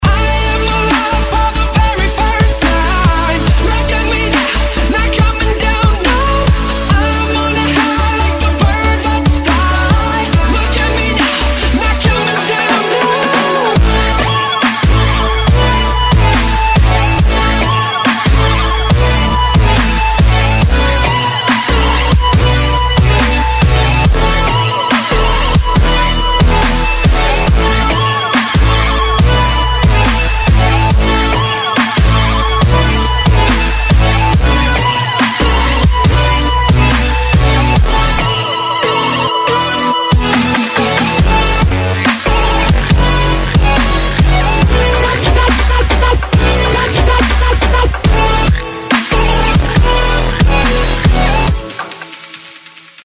• Качество: 192, Stereo
Очень красивая мелодия Дабстеп на телефон!